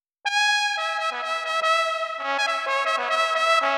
trombone_srx_orches_01.wav